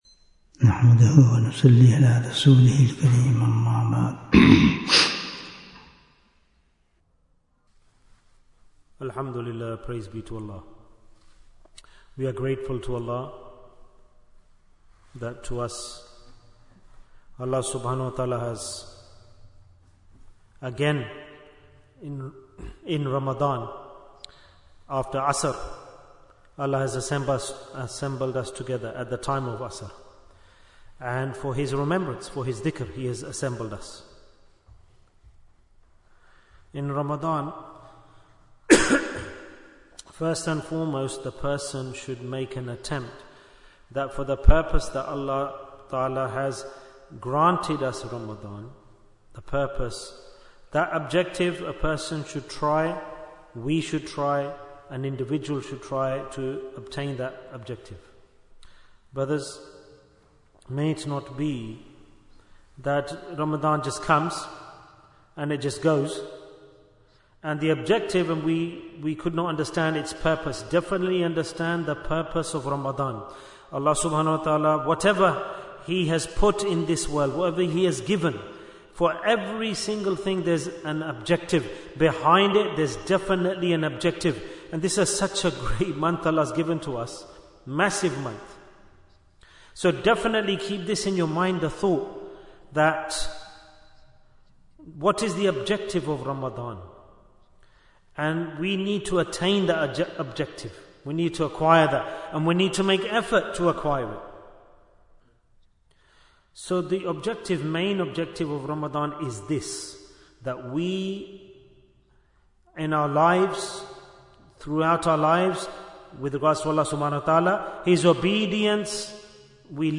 Jewels of Ramadhan 2025 - Episode 1 Bayan, 26 minutes1st March, 2025